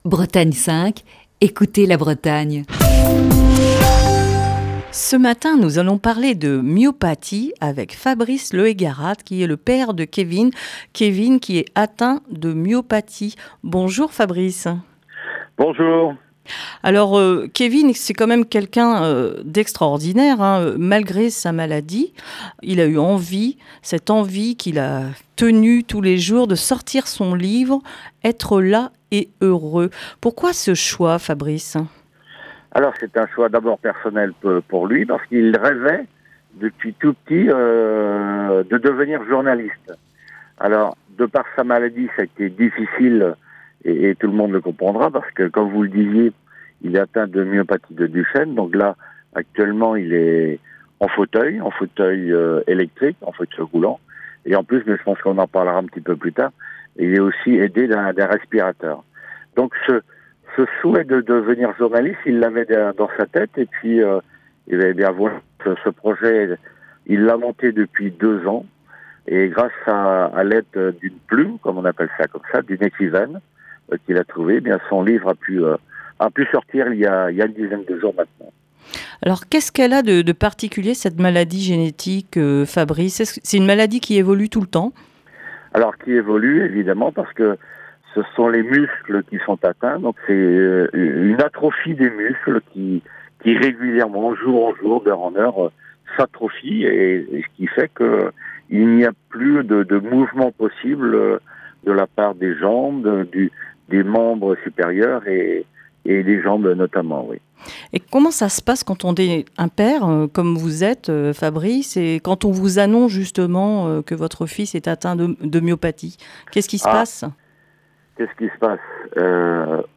au téléphone ce matin